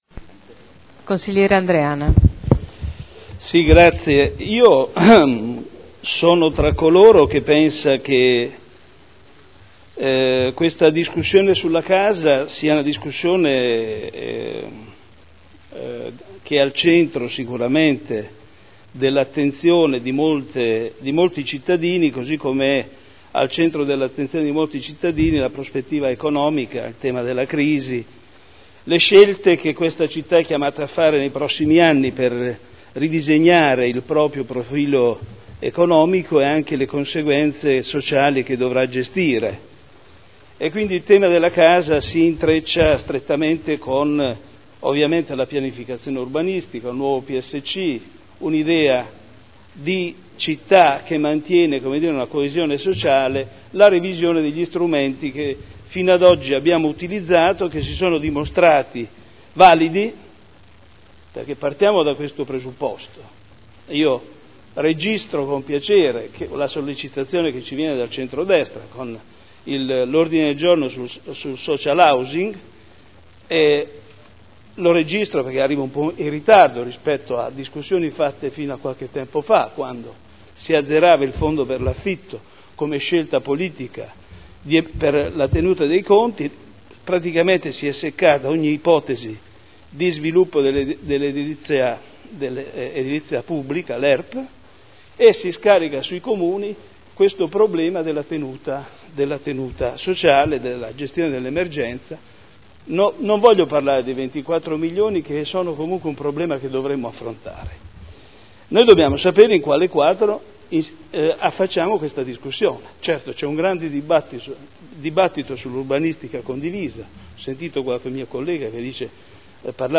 Michele Andreana — Sito Audio Consiglio Comunale
Seduta del 09/01/2012. Dibattito sui due ordini del giorno su Social Housing e riqualificazioni urbana.